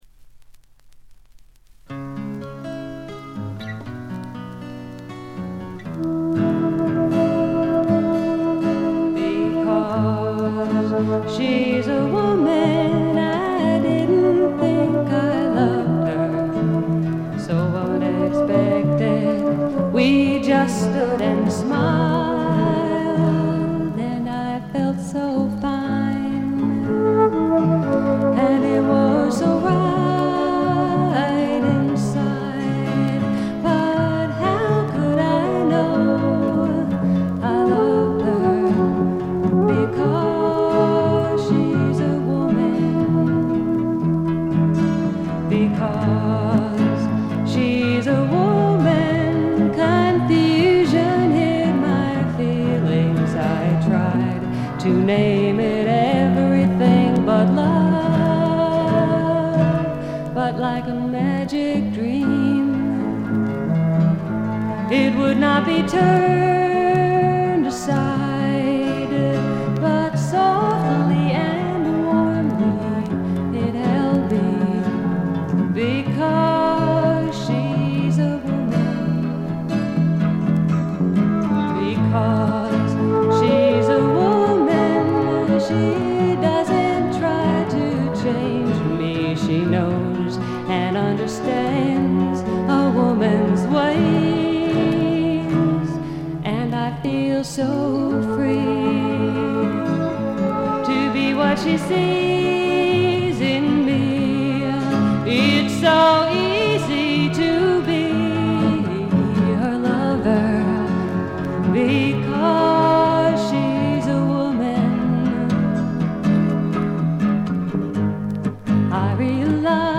細かなバックグラウンドノイズやチリプチは多め大きめに出ますが鑑賞を妨げるほどではないと思います。
自主フォーク、サイケ・フォーク界隈でも評価の高い傑作です。
試聴曲は現品からの取り込み音源です。